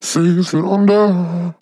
Categories: Vocals Tags: DISCO VIBES, english, feet, FORM, LYRICS, male, sample, under, wet
man-disco-vocal-fills-120BPM-Fm-4.wav